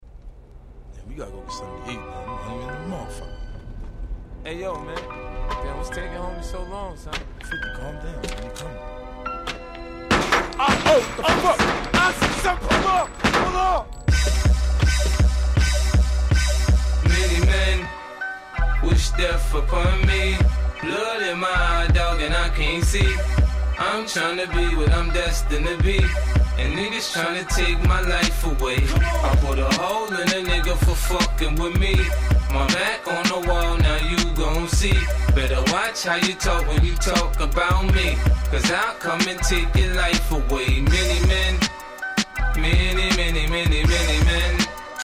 03' Super Hit Hip Hop !!